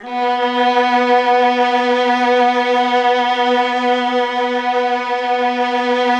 MELLOTRON .2.wav